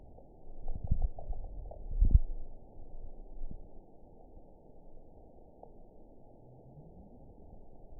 event 921903 date 12/21/24 time 13:15:39 GMT (5 months, 4 weeks ago) score 9.28 location TSS-AB03 detected by nrw target species NRW annotations +NRW Spectrogram: Frequency (kHz) vs. Time (s) audio not available .wav